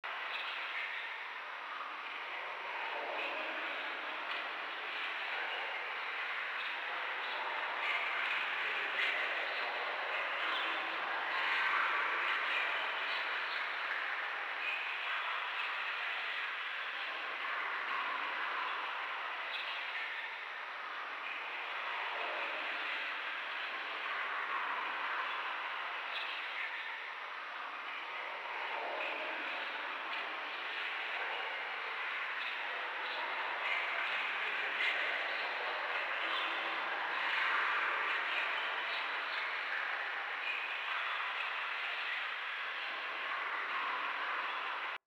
DreadFootsteps.mp3